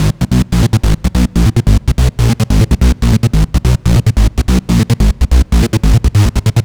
BL 144-BPM 2-G#.wav